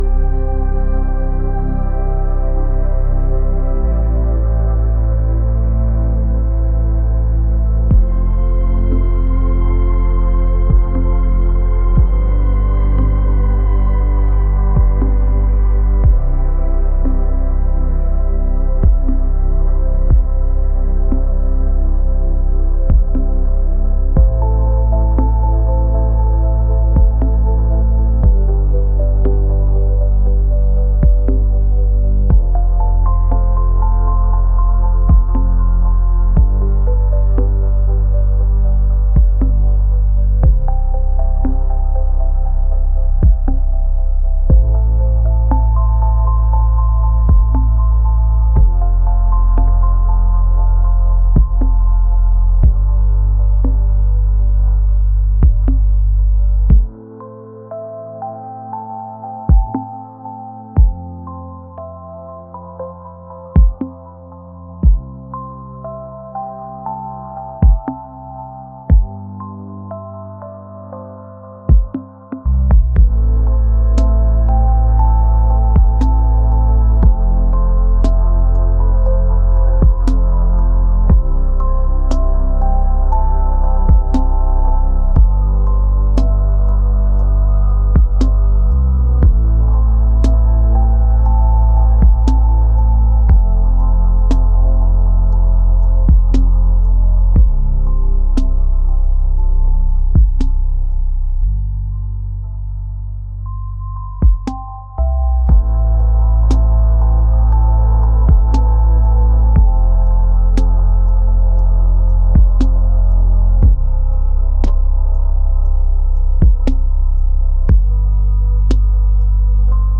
dreamy | electronic | ethereal